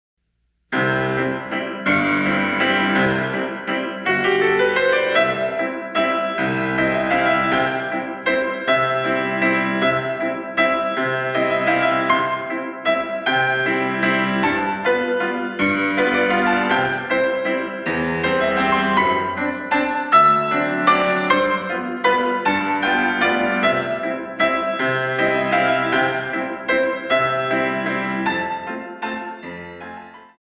In 3
64 Counts